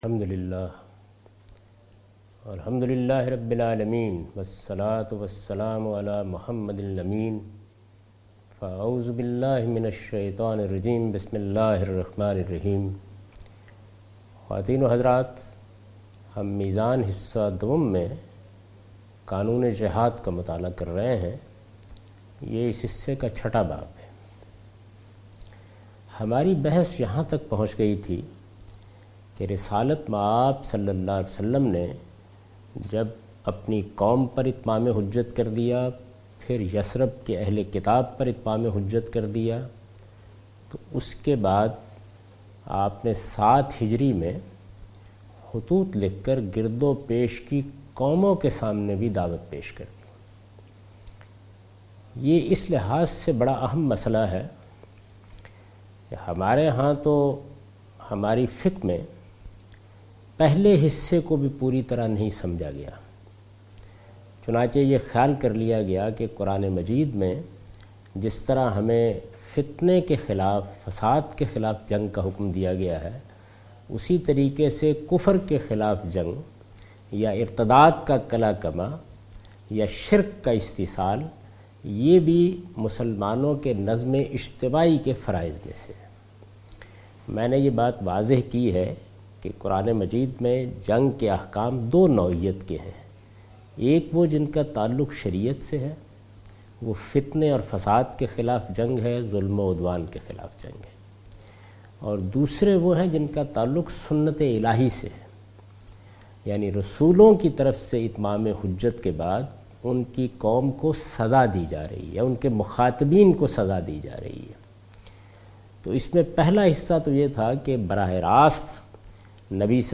A comprehensive course taught by Javed Ahmed Ghamidi on his book Meezan.